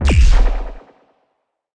Wep Forceball Explode Sound Effect
wep-forceball-explode.mp3